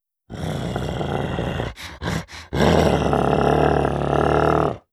Monster Roars
22. Searching Growl.wav